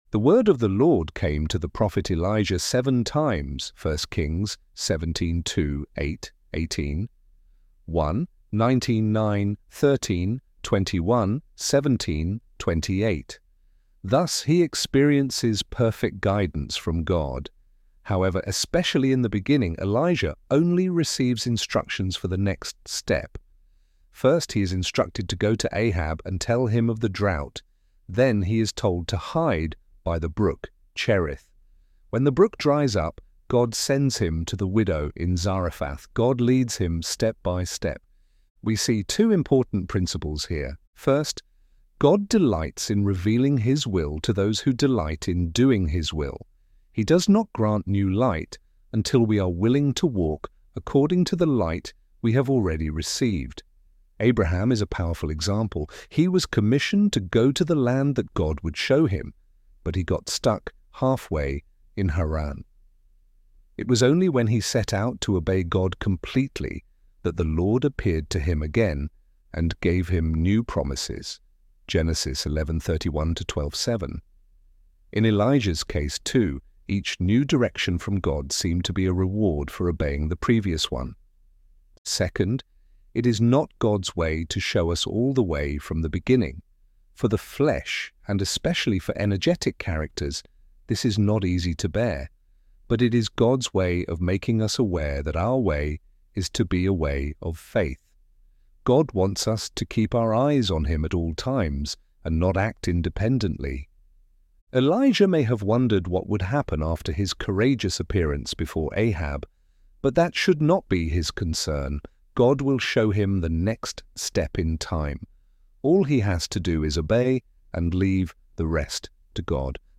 ElevenLabs_Step_by_Ste.mp3